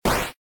flame.ogg